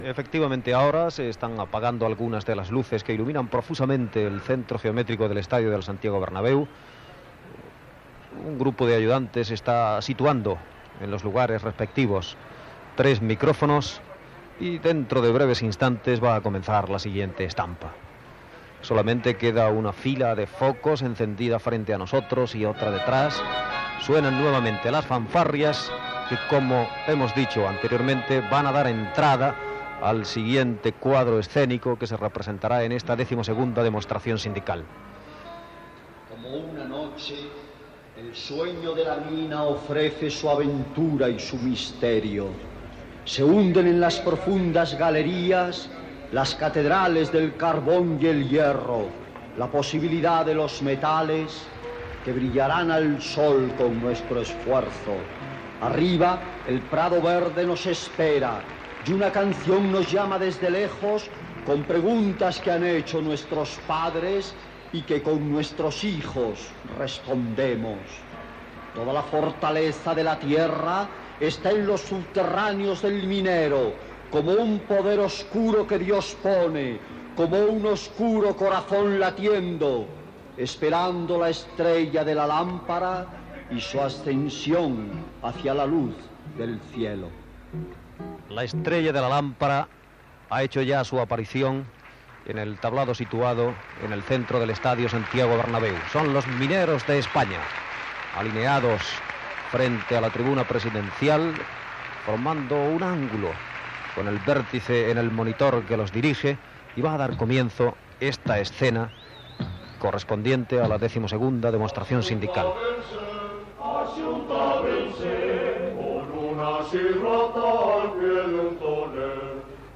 Transmissió de la "XII Demostración Sindical" des del camp Santiago Bernabeu, a Madrid.
Informatiu